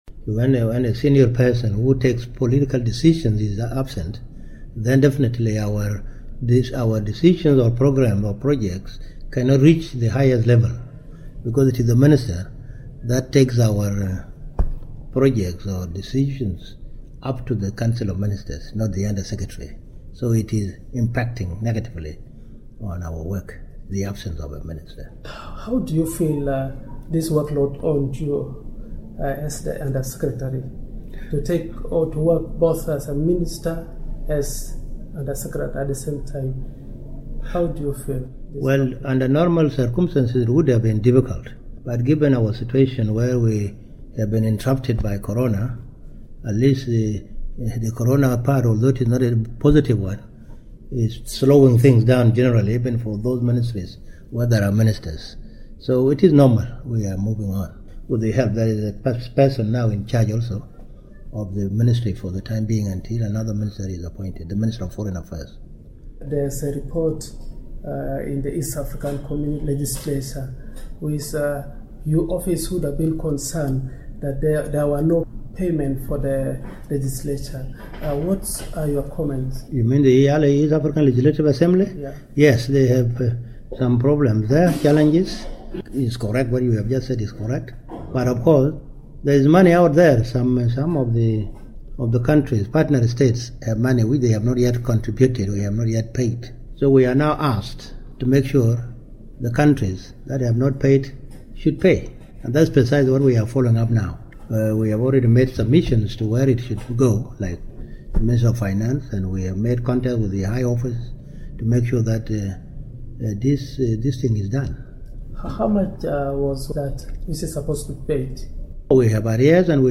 The Undersecretary for Ministry of East Africa Affairs, Mou Mou Athian Kuol revealed to Radio Miraya that the country has presented a payment plan to clear the arrears.